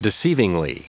Prononciation du mot deceivingly en anglais (fichier audio)
Prononciation du mot : deceivingly